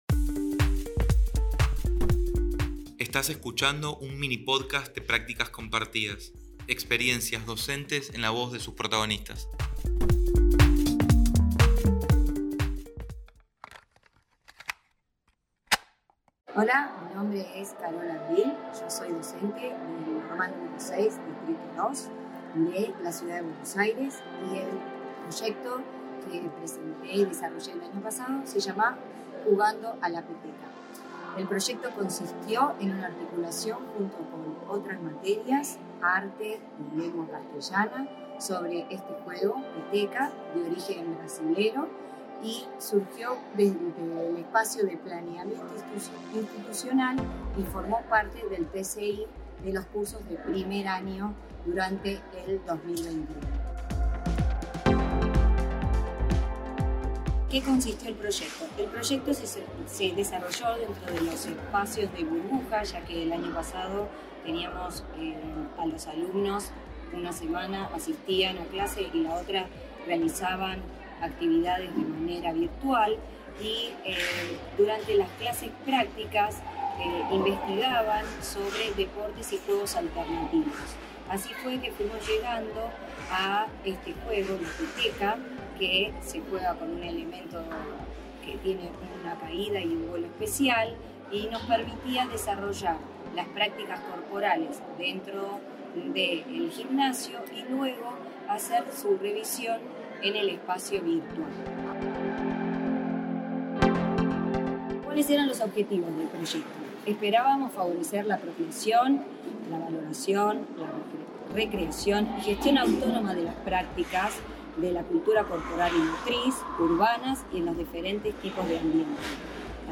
Podcast: en palabras de las docentes Peteca Experiencias Peteca Play Episode Pause Episode Mute/Unmute Episode Rewind 10 Seconds 1x Fast Forward 30 seconds 00:00